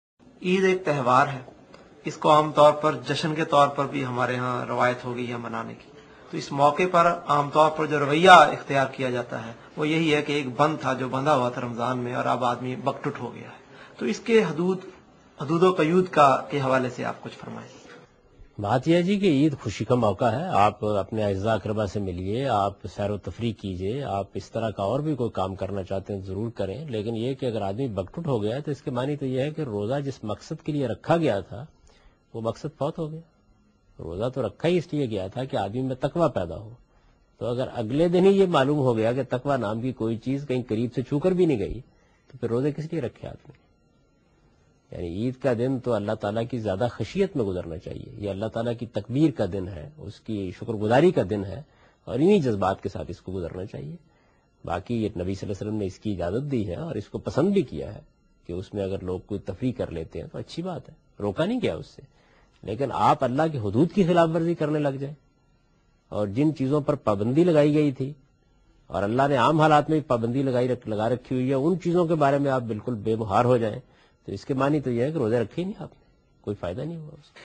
Javed Ahmed Ghamidi is answering a question regarding "How should we spend the Eid day?"